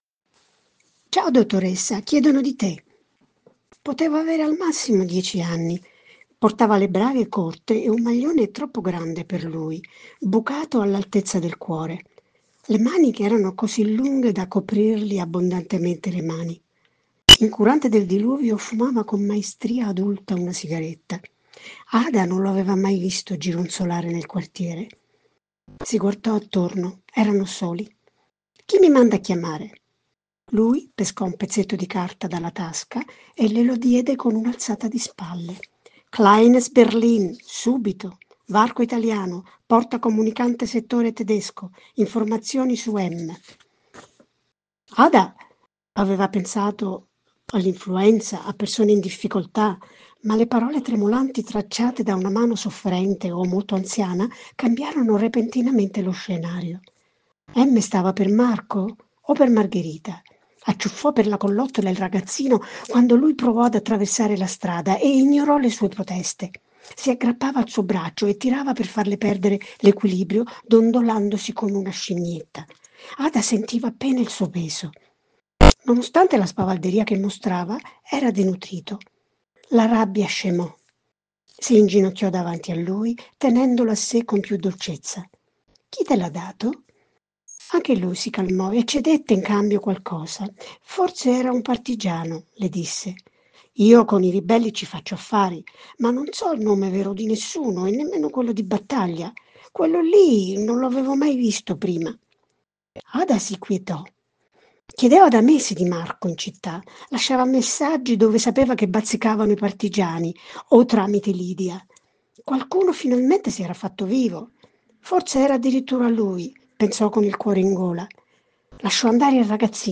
Ad alta voce:  Bambini adulti  p.179